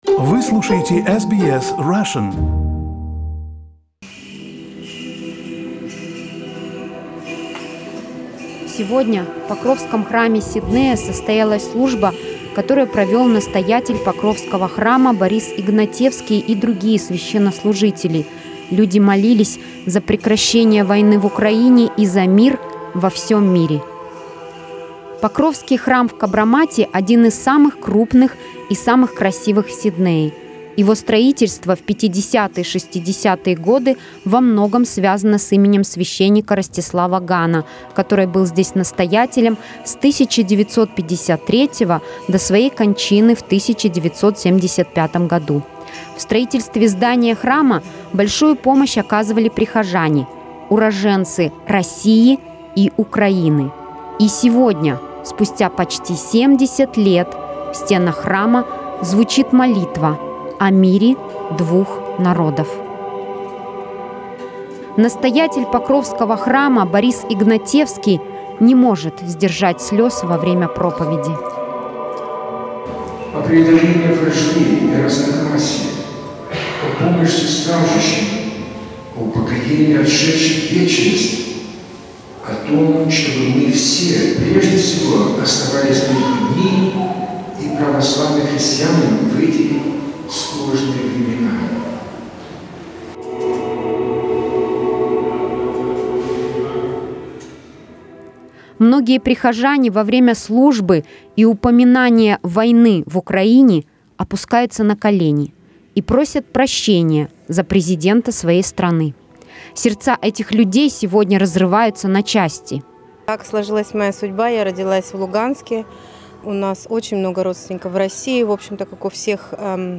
SBS Russian attended the Sunday service at Russian Orthodox Church of the Intercession of the Holy Virgin.